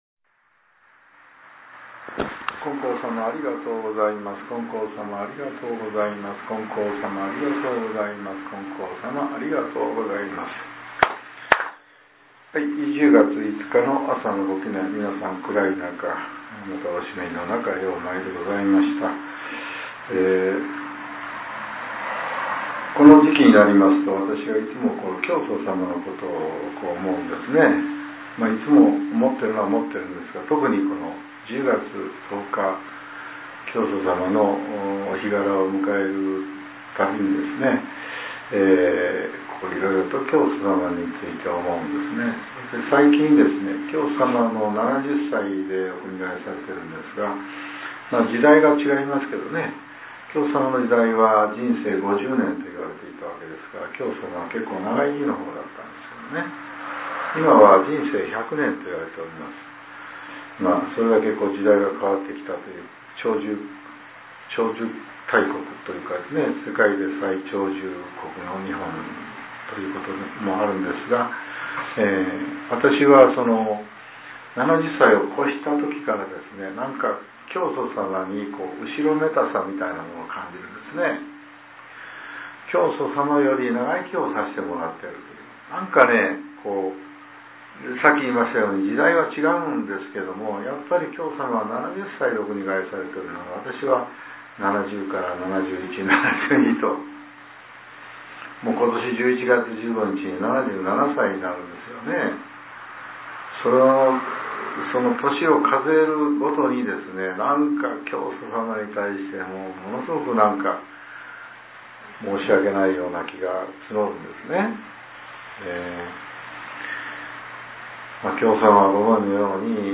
令和７年１０月５日（朝）のお話が、音声ブログとして更新させれています。